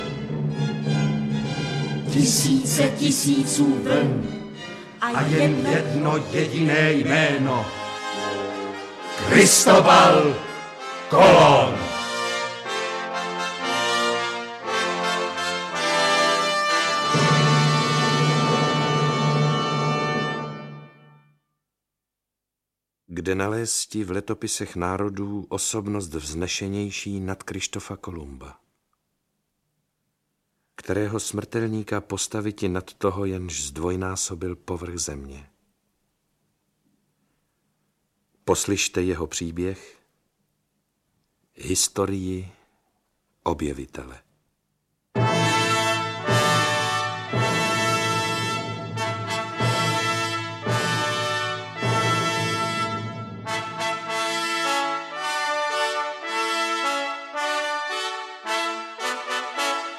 Audiobook
Read: Otakar Brousek